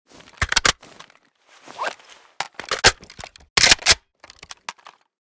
groza_reload_empty.ogg.bak